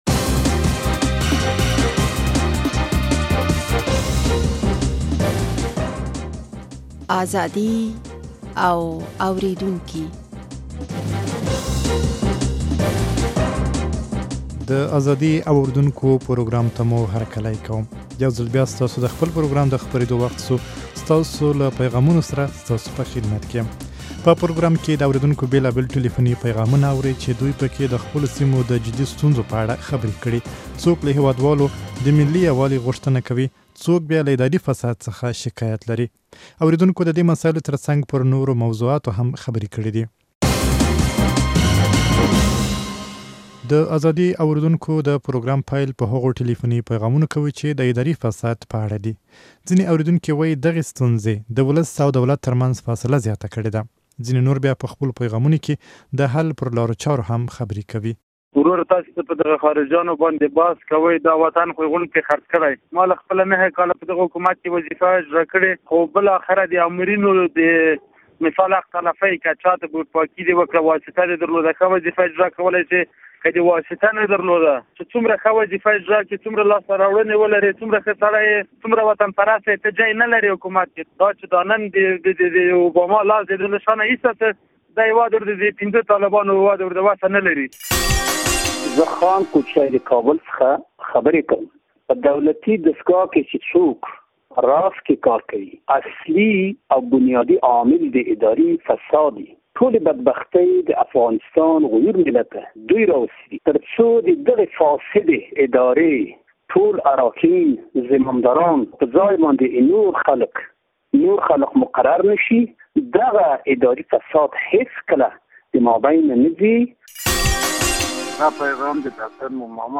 په پروګرام کې د اورېدونکو بېلابېل ټليفوني پيغامونه اورئ، چې دوى په کې د خپلو سيمو د جدي ستونزو په اړه خبرې کړي، څوک له هېوادوالو د ملي يووالي غوښتنه کوي او څوک بيا له اداري فساد څخه شکايت لري.